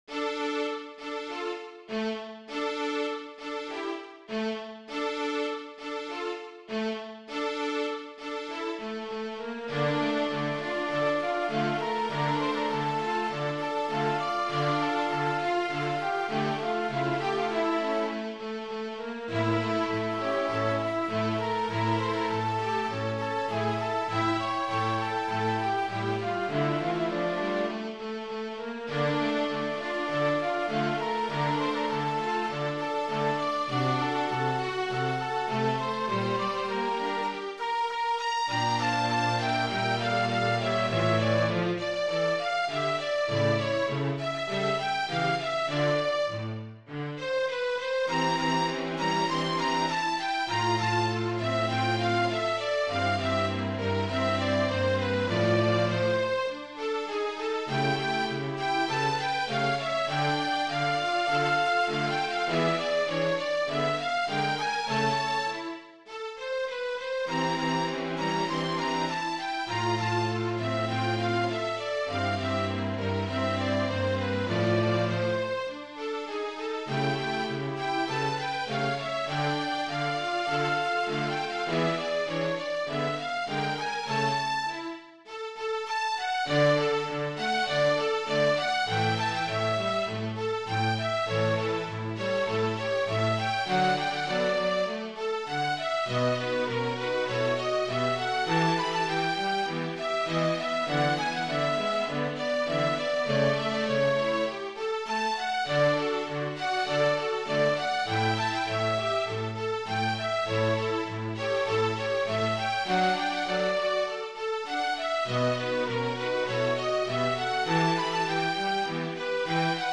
タンゴです。
参考音源はあくまで参考に、コンピューターで表現できない強弱や間があります。